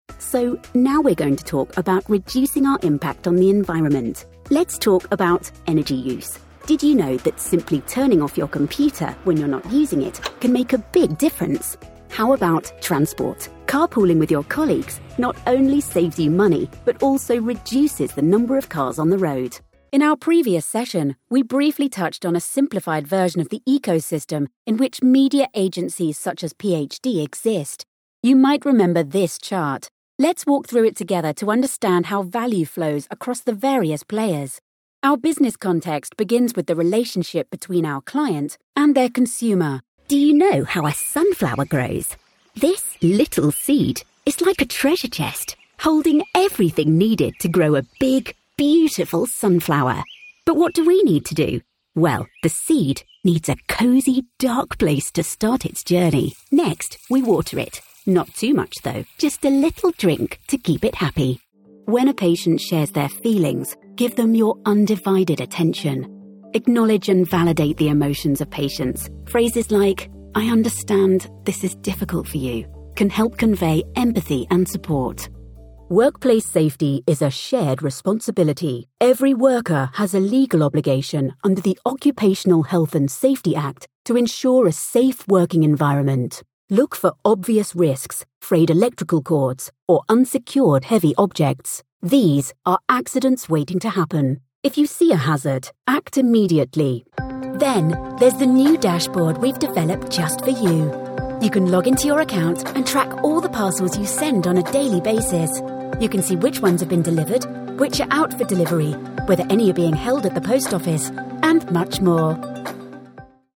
Engels (Brits)
Natuurlijk, Veelzijdig, Vriendelijk, Warm, Zakelijk
E-learning